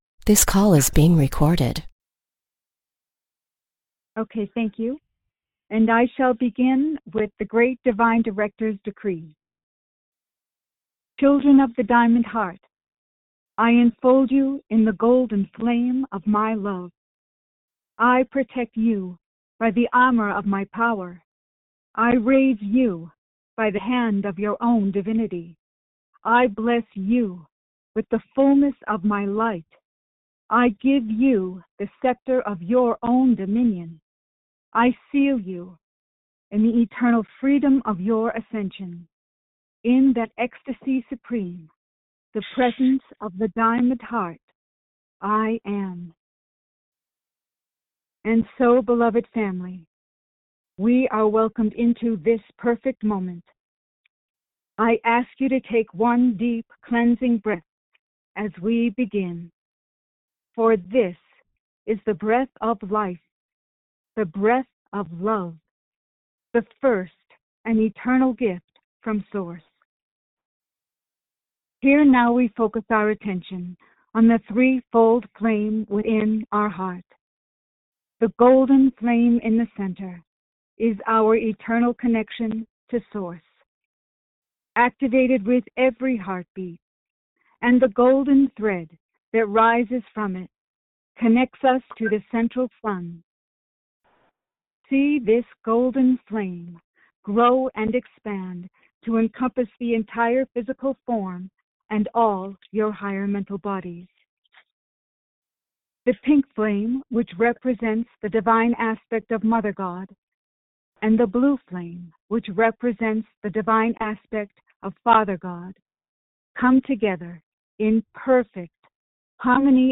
Audio Recording Meditation – Minute (00:00) Click on the “Audio Recording” link about to join-in with Lord Sananda in group meditation. Channeling – Minute (20:19) I am Sananda, And I come to be with you at this time in these times of break changes, great changes that are right on the cusp of coming into fruition.